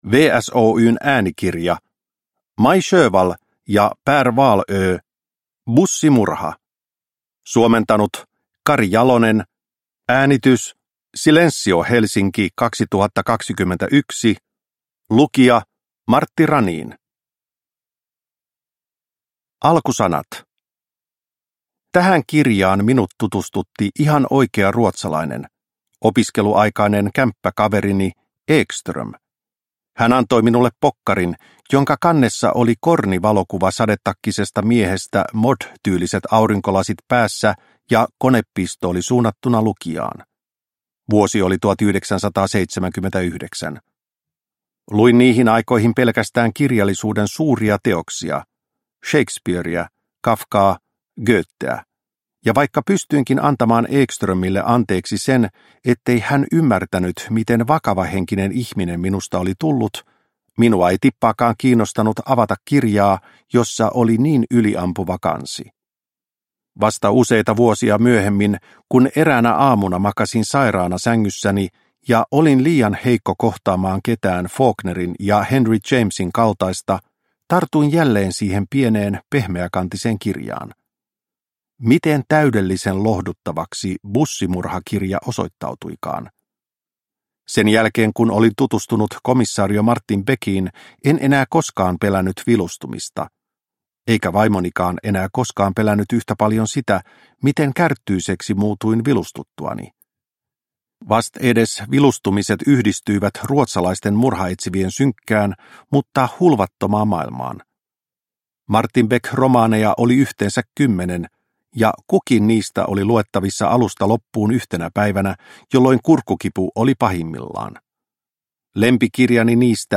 Bussimurha – Ljudbok – Laddas ner